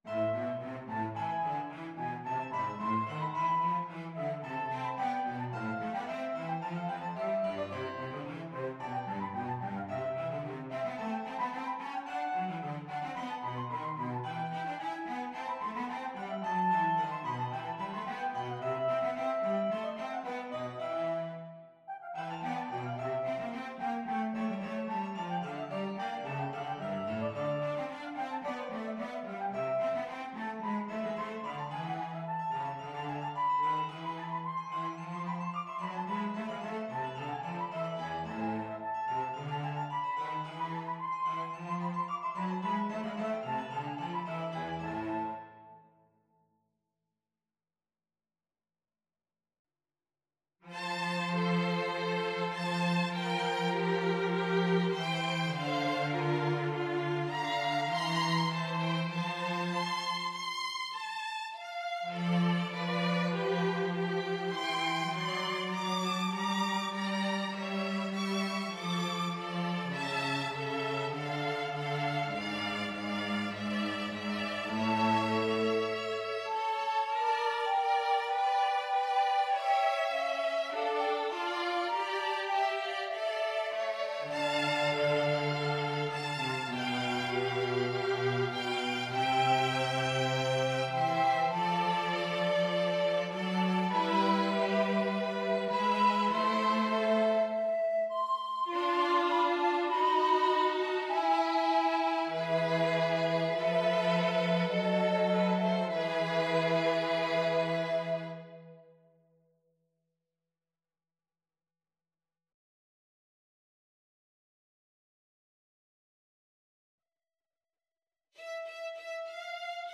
FluteViolin 1Violin 2Cello
Classical (View more Classical Flute and Ensemble Music)